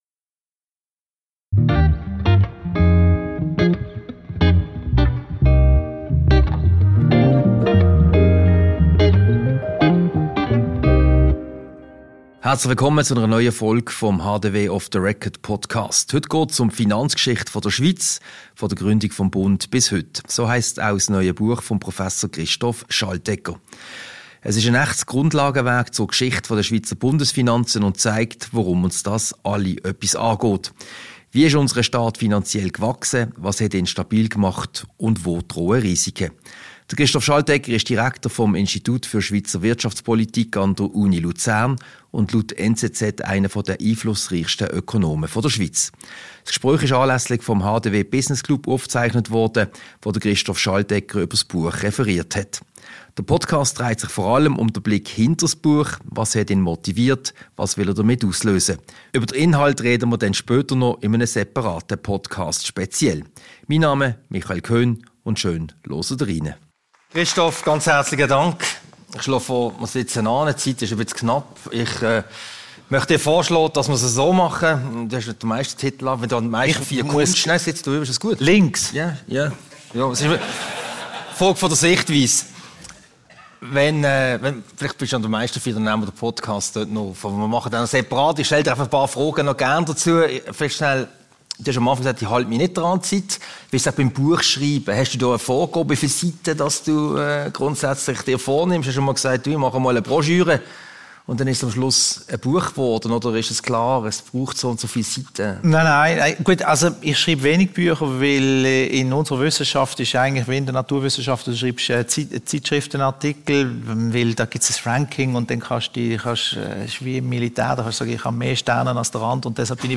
Diese Podcast-Ausgabe wurde anlässlich des HDW Business Lunchs vom 22. Mai im Haus der Wirtschaft HDW aufgezeichnet.